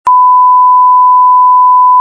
1_1kHz_0dB.mp3